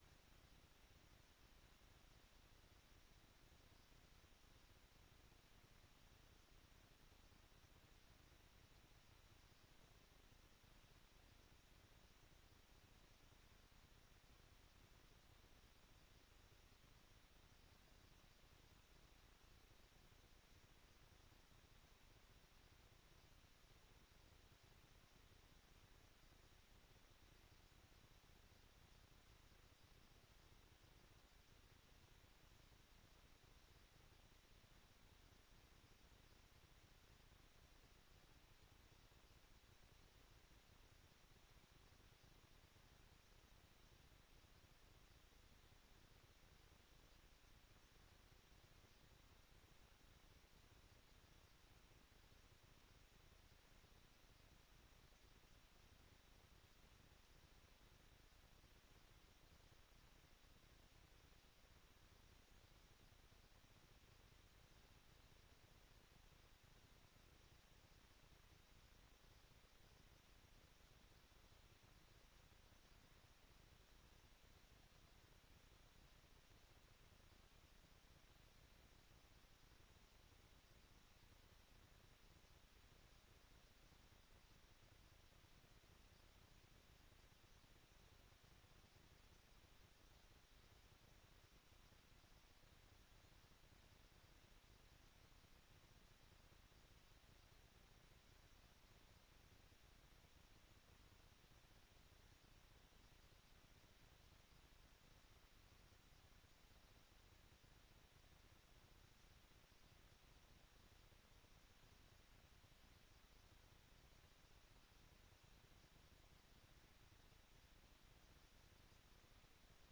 Download de volledige audio van deze vergadering